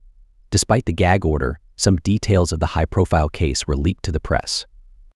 Play.ht-Despite-the-gag-order-some-details-of.wav